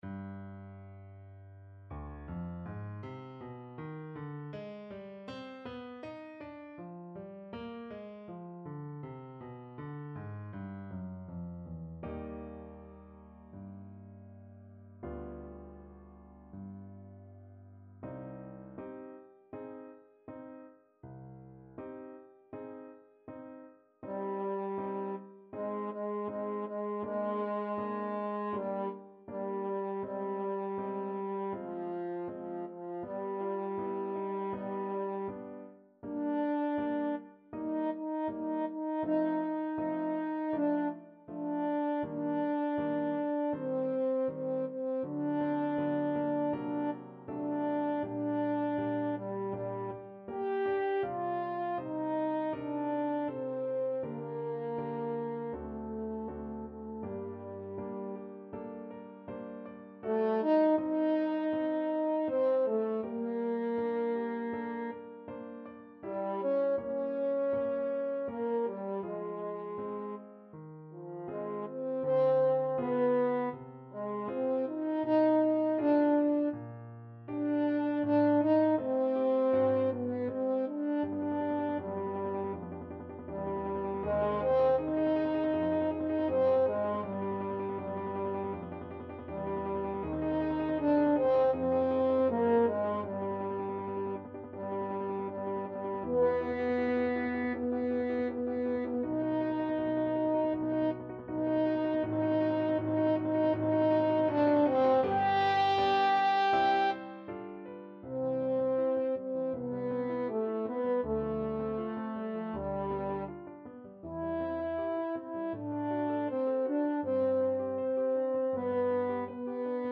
French Horn
C minor (Sounding Pitch) G minor (French Horn in F) (View more C minor Music for French Horn )
~ = 100 Molto moderato =80
4/4 (View more 4/4 Music)
Classical (View more Classical French Horn Music)